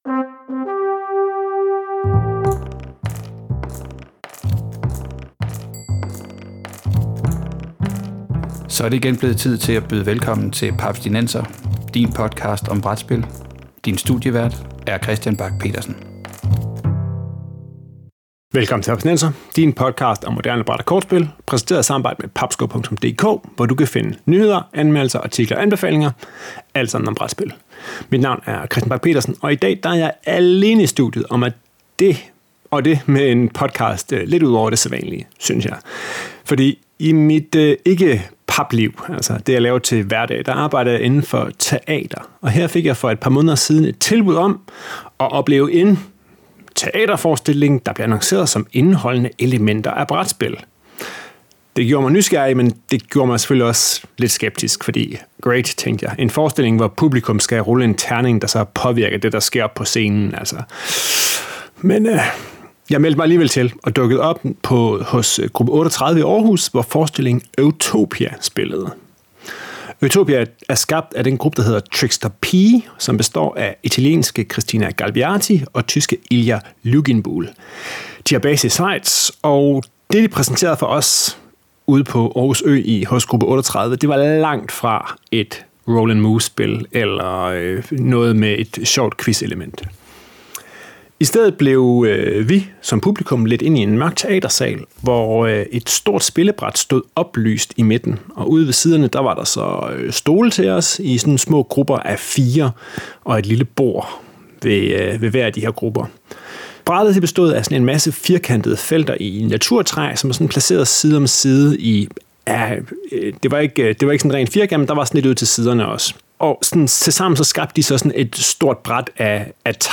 Det, og meget mere om forestillingen Eutopia i dette interview.